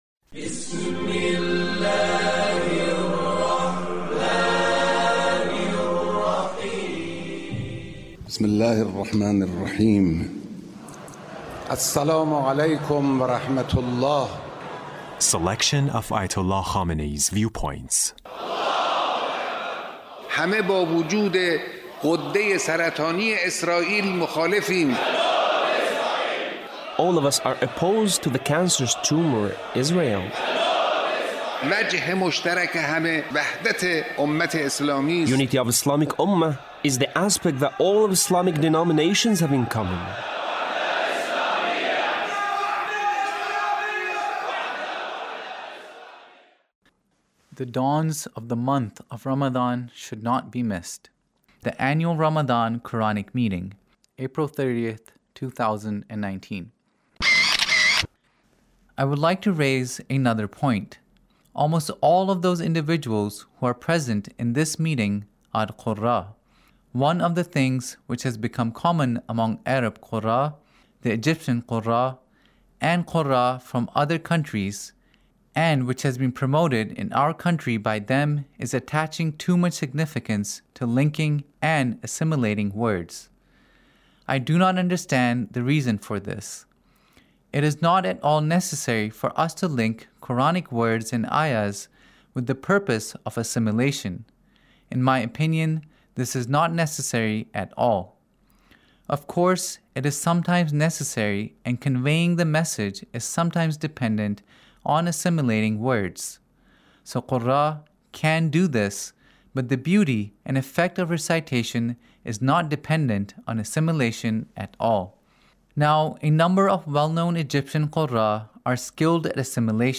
Leader's Speech (1914)